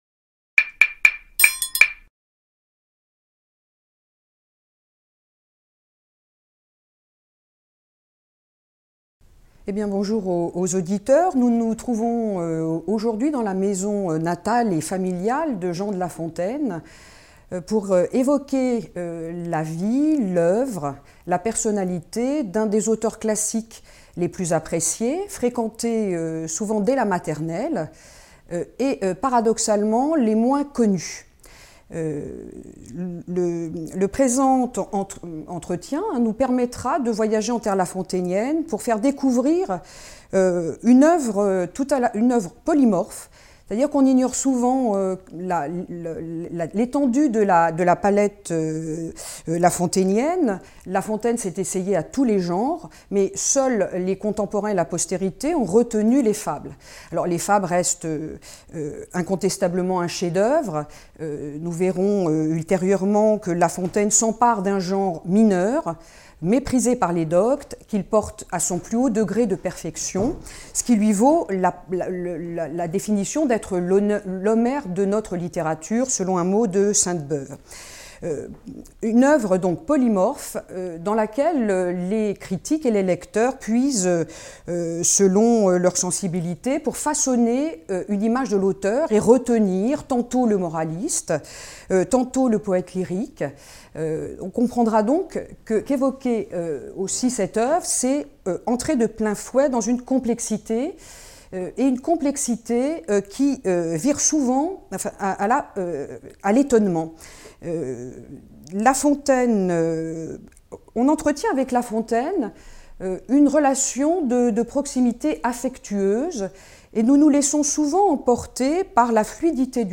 docteur HDR en littérature Vidéo tournée en la Maison de La Fontaine à Château-Thierry (Aisne), à l'occasion du quadricentenaire de sa naissance (juillet 1621)